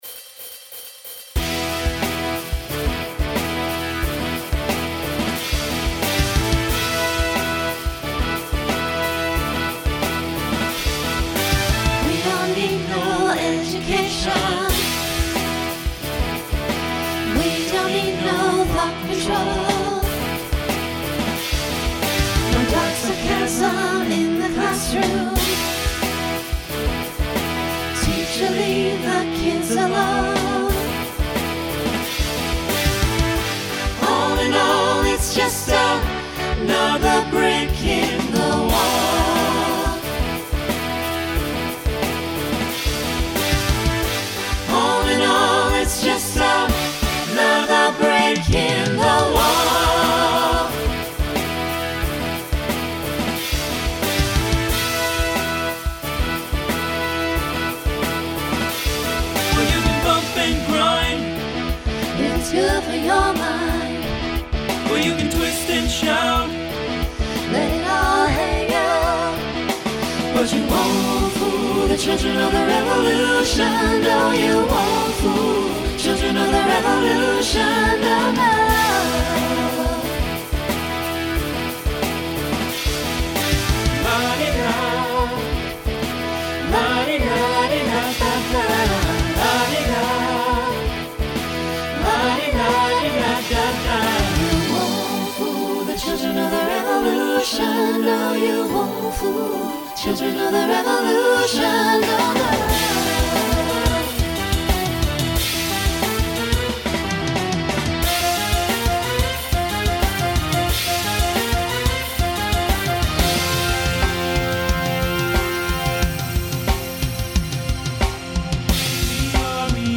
Voicing SATB Instrumental combo Genre Rock
2000s Show Function Mid-tempo